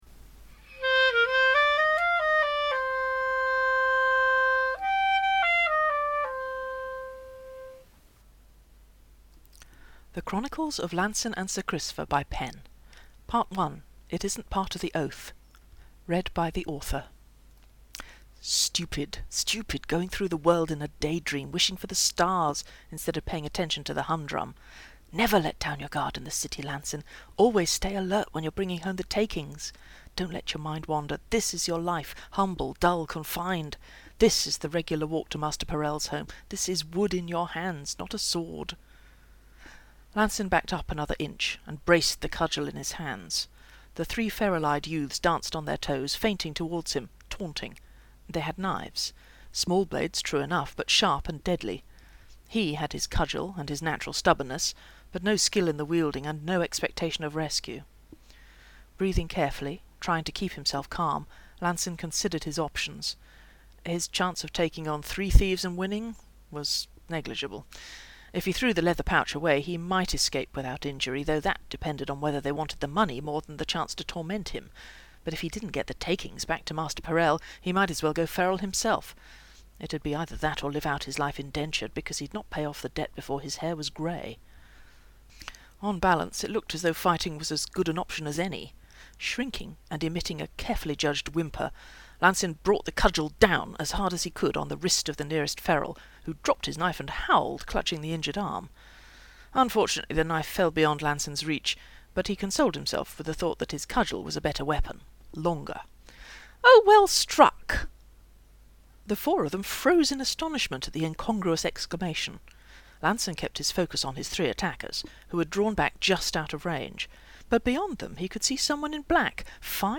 This story is available as podfic (mp3):